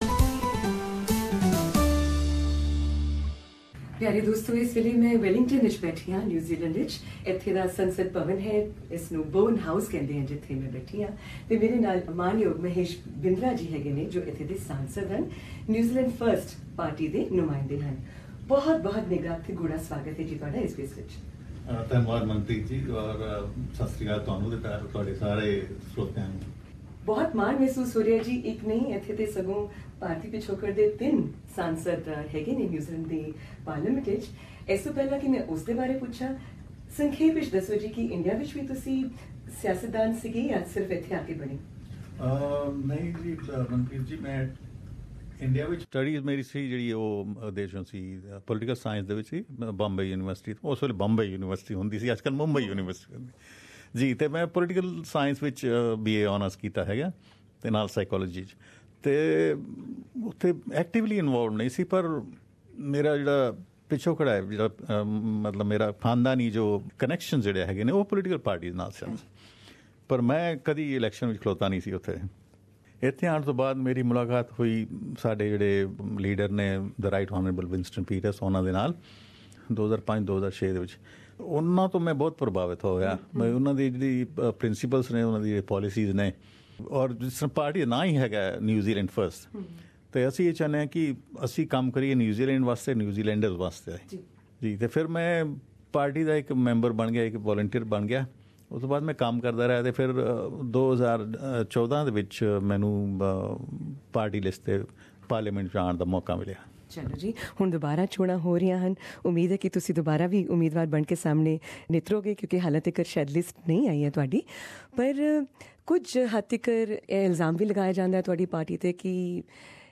Mahesh Bindra, Member of Parliament in New Zealand Source: SBS
READ MORE My party has been wrongly accused of being racist and xenophobic Mr Bindra also denied that his party is racist or xenophobic, in the above interview (which was recorded in English).